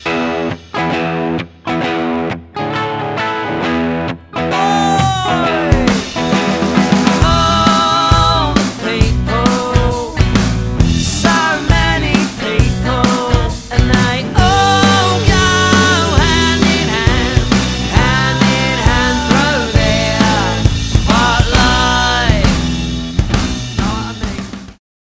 • Unique and stylised versions of classic anthems